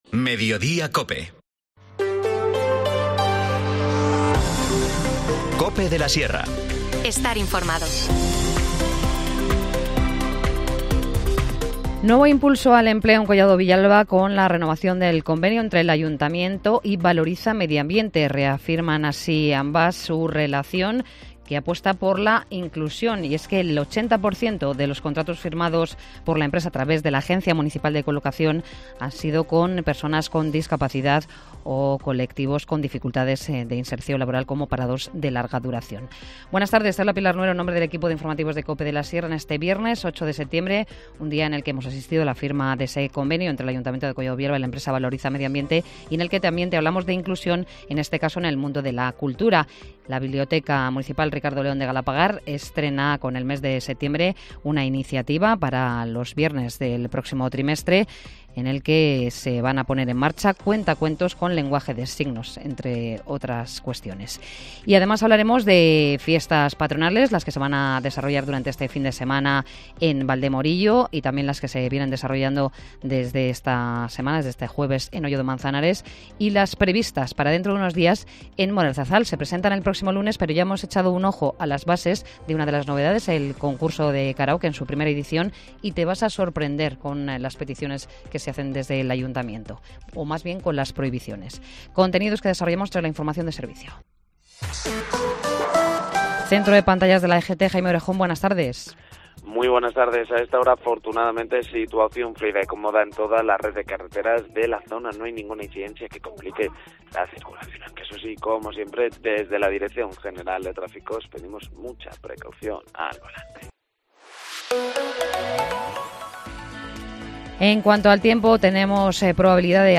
Informativo | Mediodía en Cope de la Sierra, 8 de septiembre de 2023